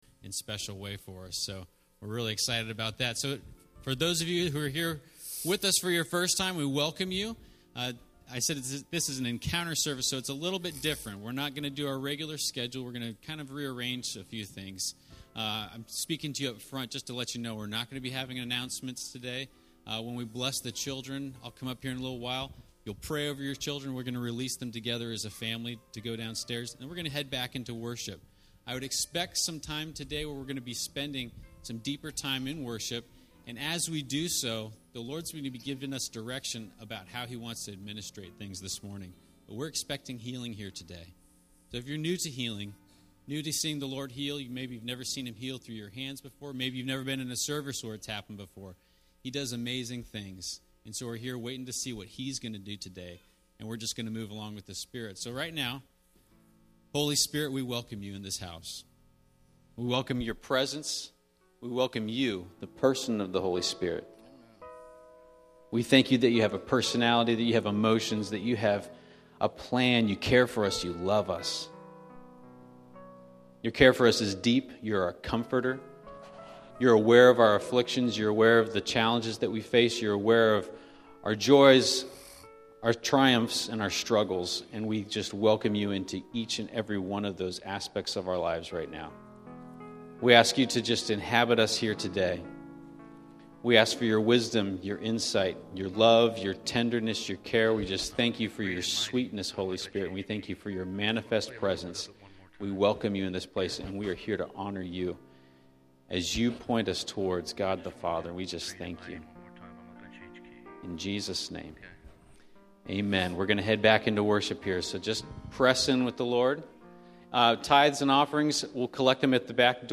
Encounter Service